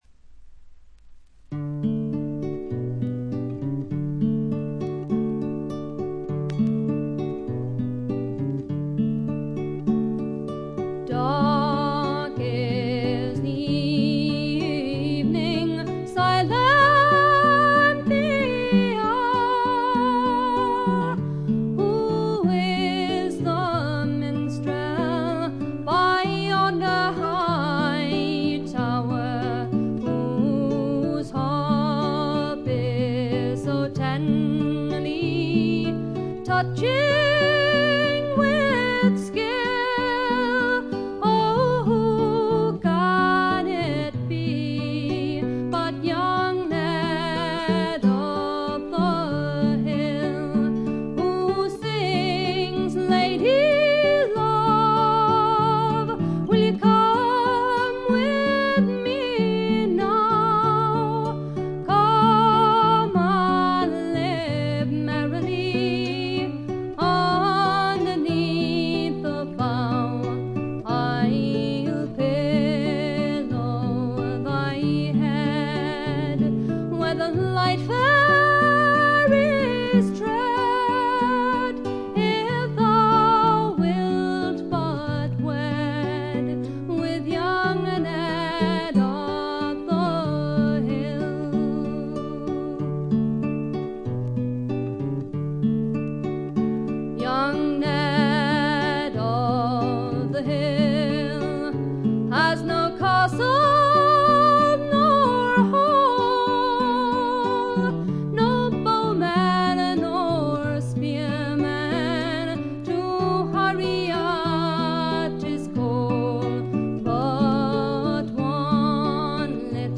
特にオルガンのひなびた音色とかたまらんものがあります。
あまりトラッド臭さがなくほとんどドリーミーフォークを聴いているような感覚にさせてくれる美しい作品です。
試聴曲は現品からの取り込み音源です。
Fiddle, Recorder, Vocals